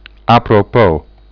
1apropos apro-pos (niet: a-propos) /
2apropos apro-pos (niet: a-propos) /aprop'o/ bijw. van pas, gelegen: dat kwam niet zeer apropos.